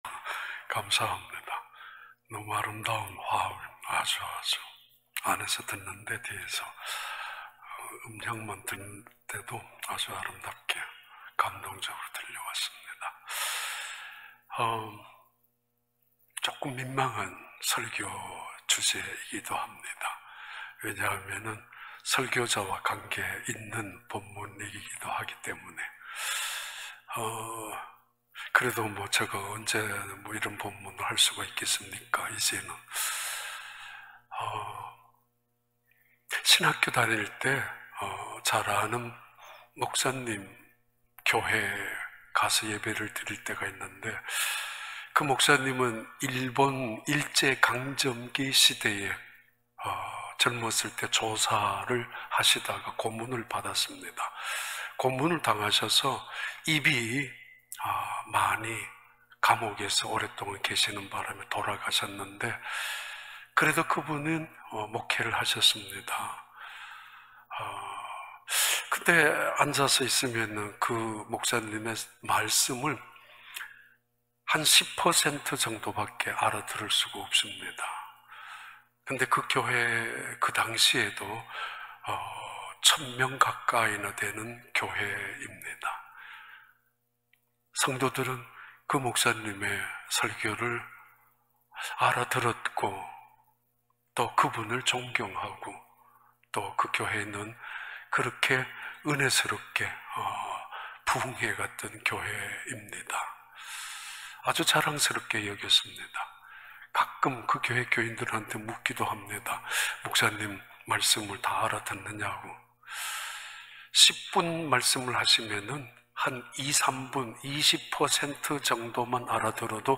2021년 4월 11일 주일 4부 예배